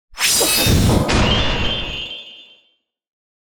mandrake fvttdata/Data/modules/psfx/library/ranged-magic/generic/missile/001